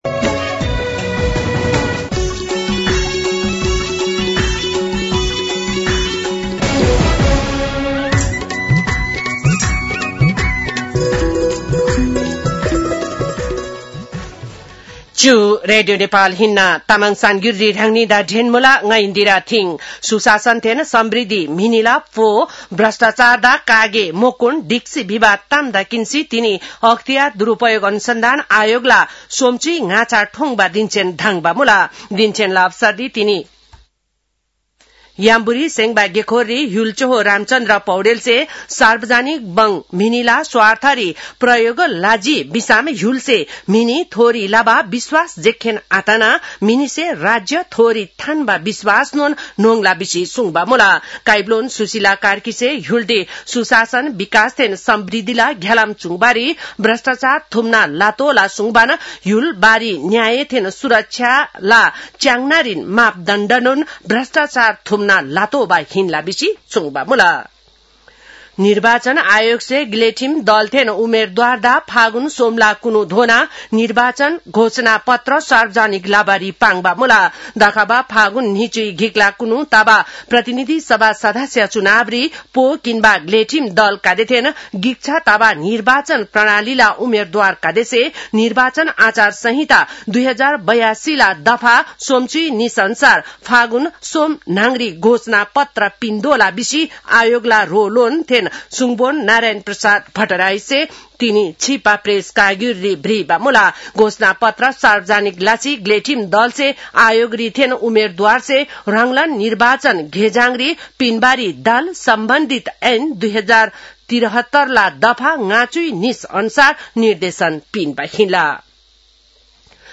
तामाङ भाषाको समाचार : २८ माघ , २०८२